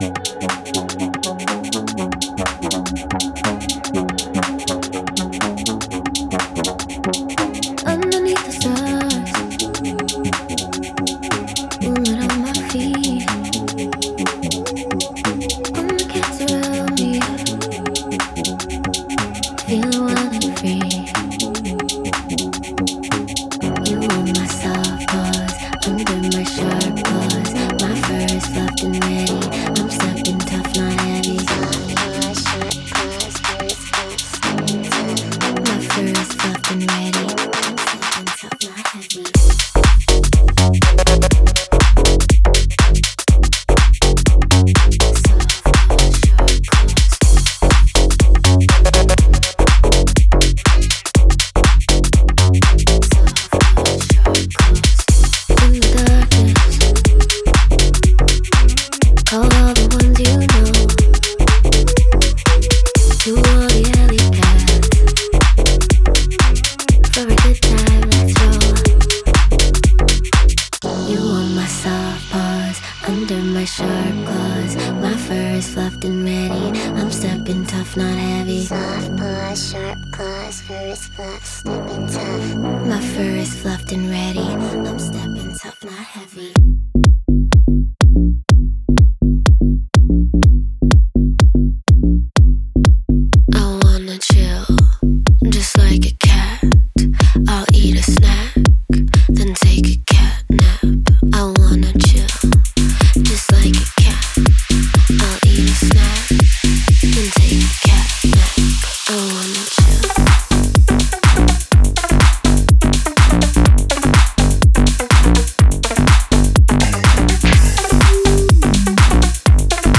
FXのワンショットにはライザー、フォーラー、インパクト、アンビエンスが含まれ、アレンジに形を与えます。
デモサウンドはコチラ↓
Genre:Pop